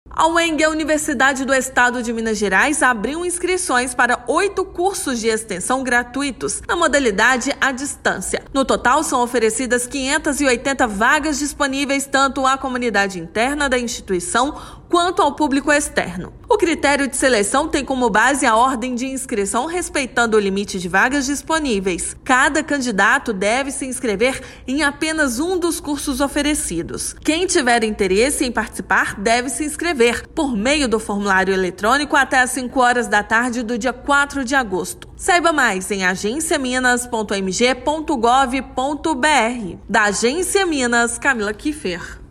[RÁDIO] Uemg oferta centenas de vagas em cursos de extensão gratuitos
No total, são oferecidas 580 vagas, disponíveis tanto à comunidade interna da instituição, quanto ao público externo. Ouça matéria de rádio.